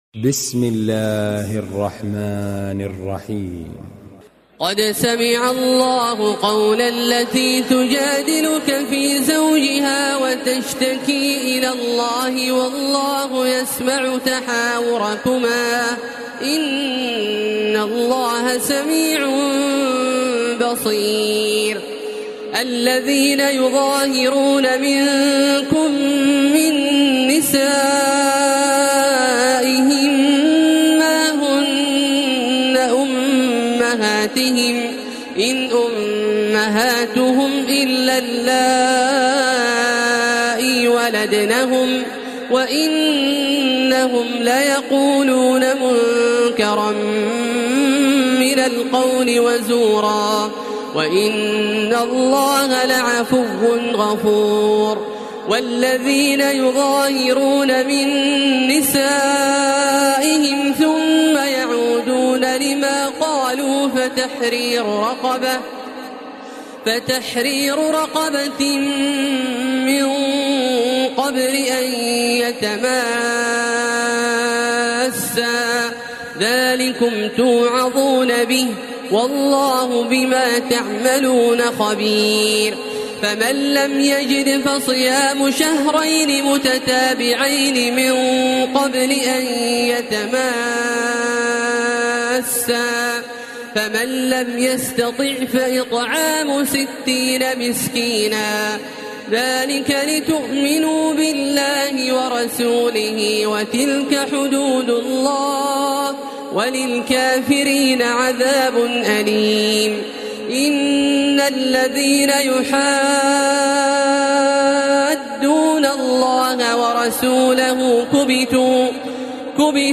تراويح ليلة 27 رمضان 1433هـ من سورة المجادلة الى الصف Taraweeh 27 st night Ramadan 1433H from Surah Al-Mujaadila to As-Saff > تراويح الحرم المكي عام 1433 🕋 > التراويح - تلاوات الحرمين